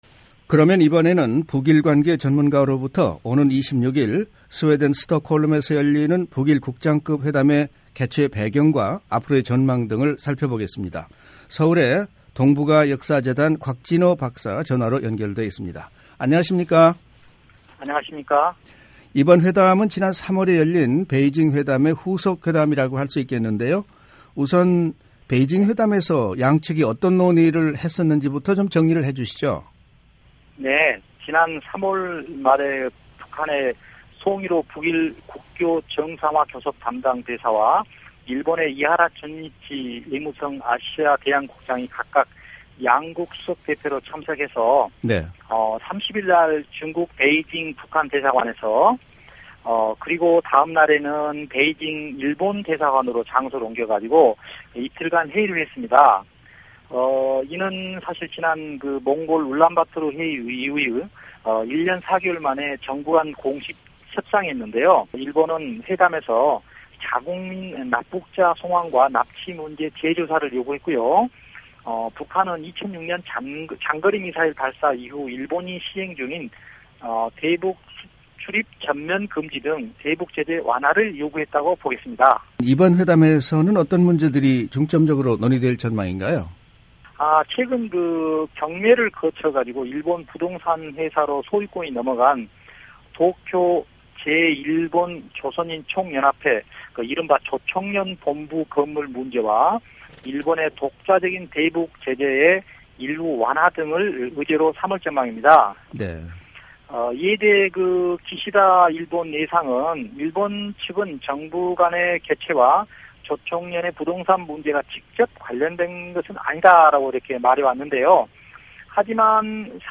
인터뷰